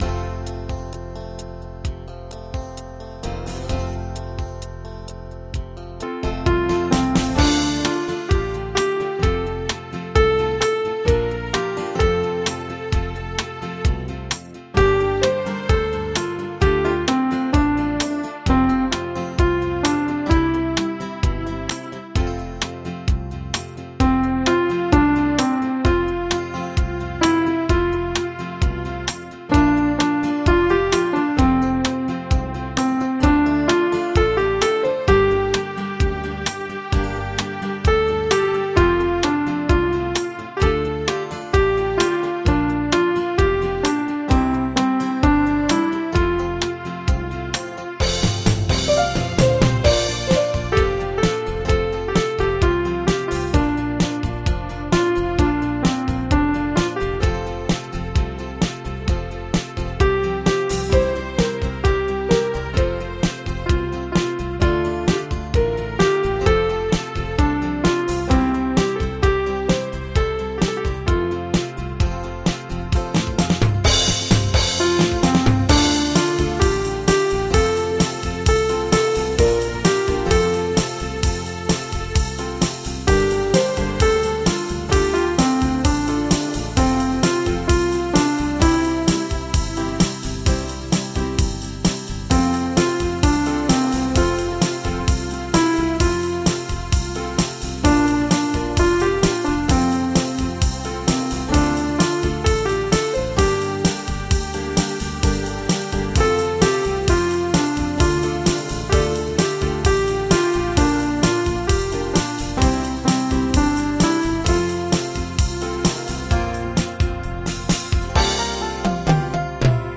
Audio Midi Bè 02: download